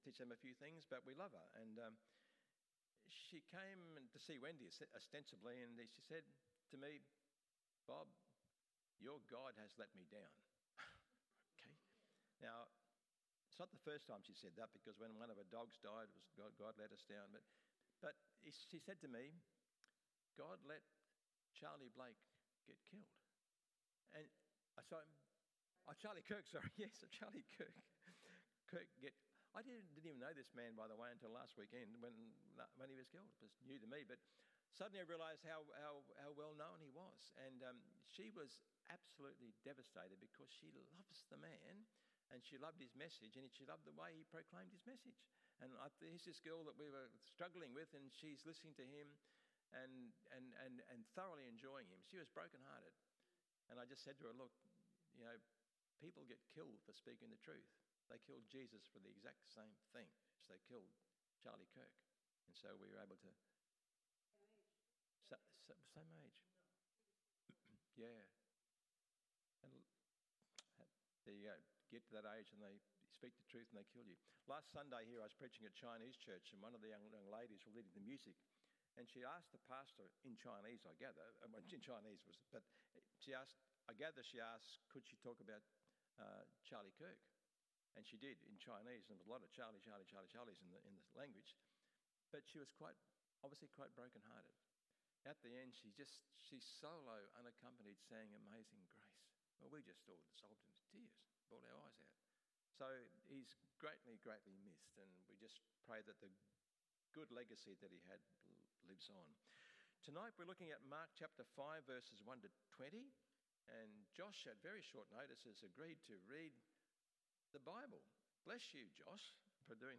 A Storm Of A Different Kind PM Service
sermon podcasts